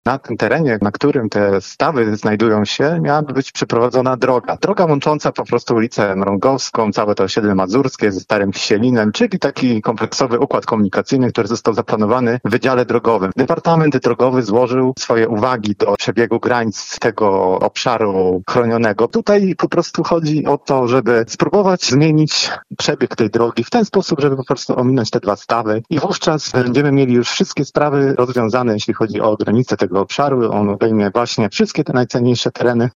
Jak wyjaśnił radny Paweł Zalewski tym razem rozmowy dotyczyły terenu przy ul. Róży Wiatrów, na którym znajdują się dwa niewielkie, cenne przyrodniczo stawy: